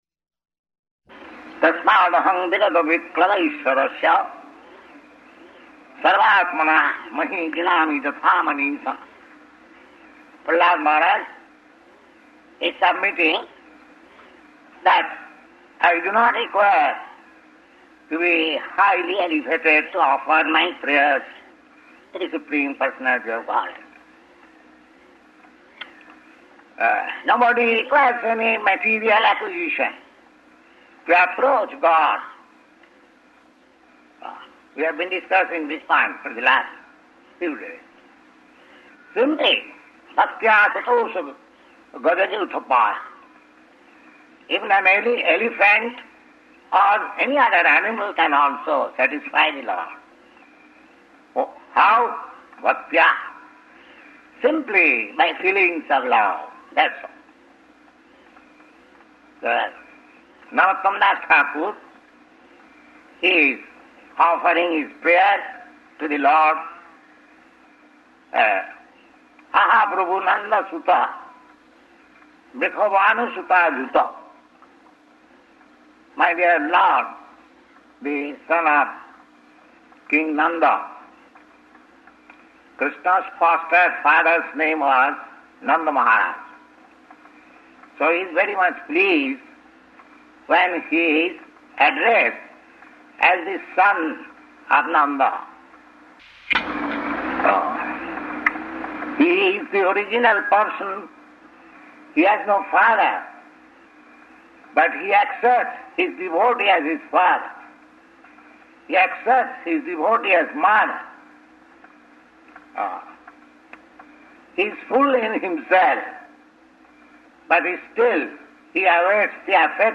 Type: Srimad-Bhagavatam
Location: Montreal
[Fluctuating audio]